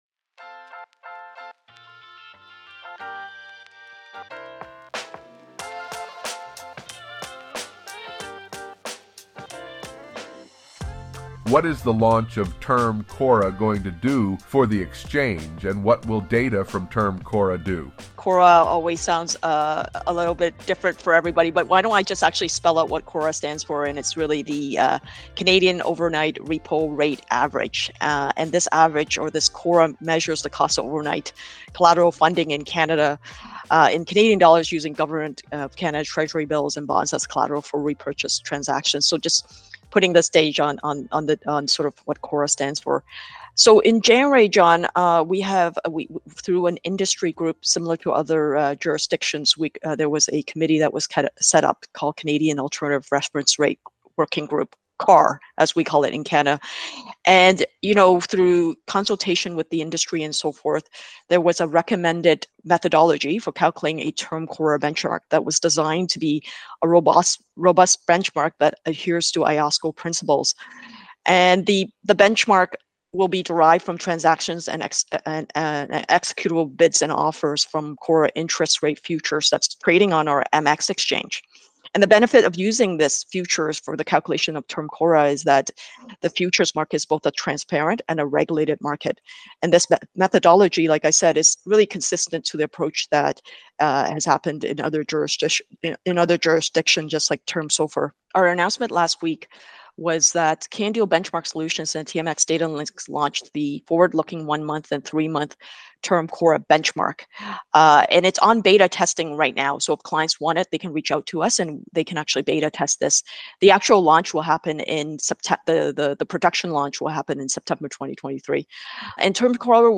John Lothian News Interviews